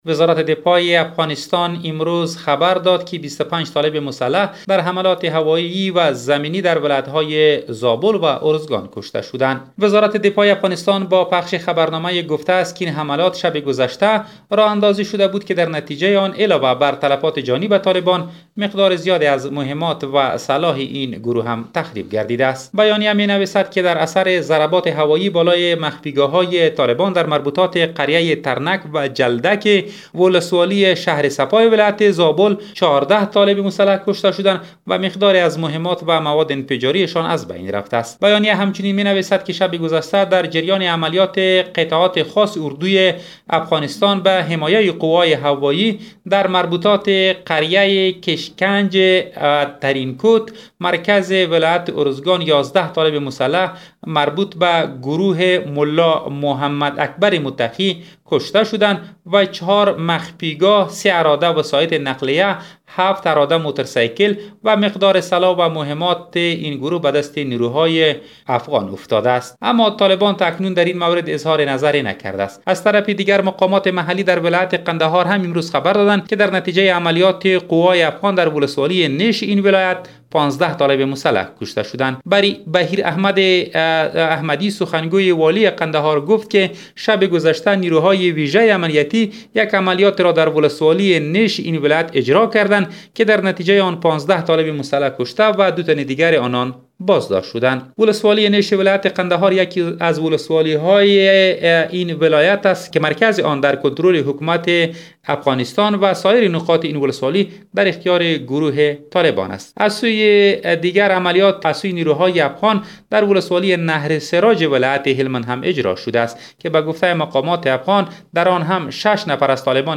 جزئیات در گزارش خبرنگار رادیودری: